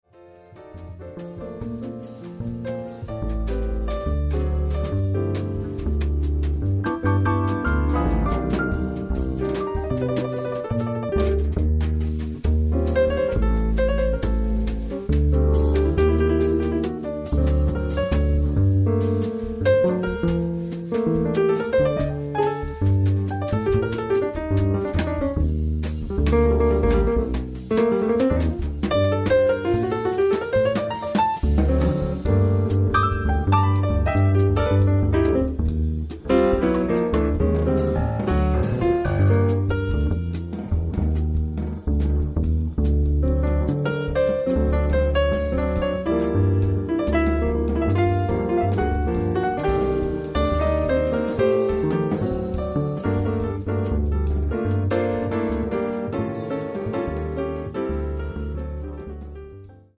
piano
bass
drums
l'atmosfera si fa struggente ed esclusiva